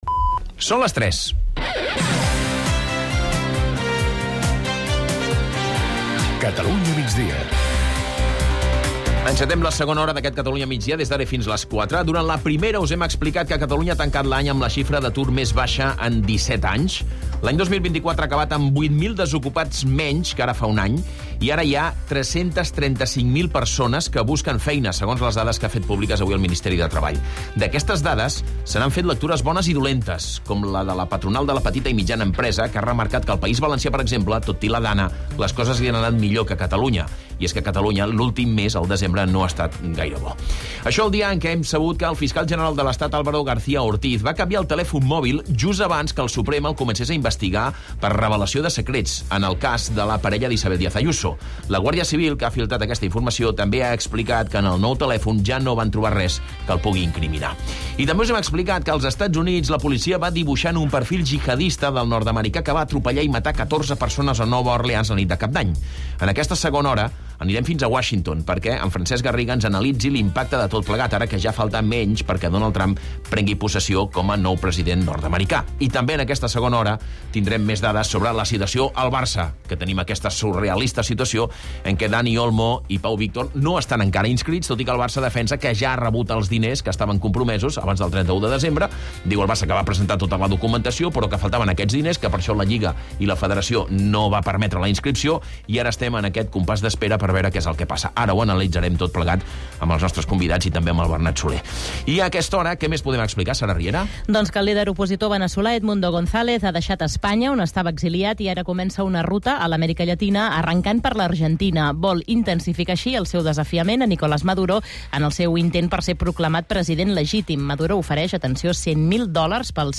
… continue reading 503 odcinków # Society # Corporaci Catalana de Mitjans Audiovisuals, SA # Catalunya Rdio # News Talk # News